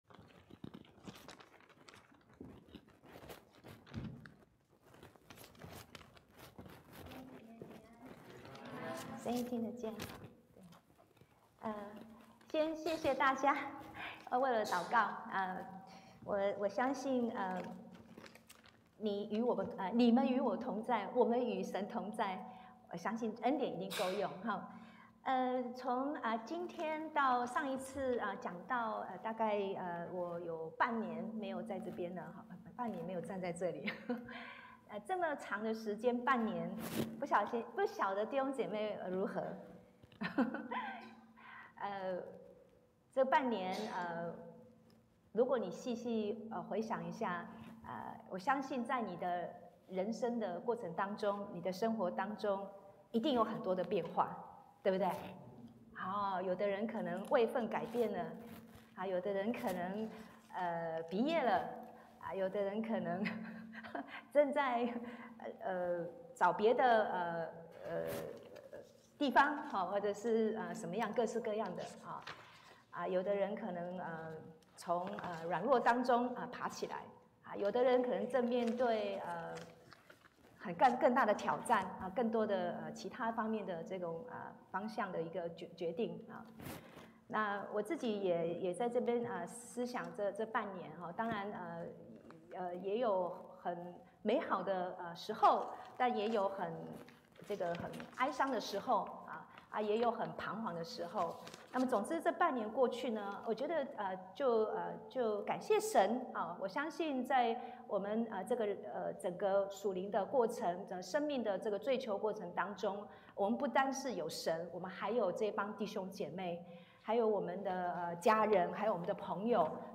Sermons | 基督教主恩堂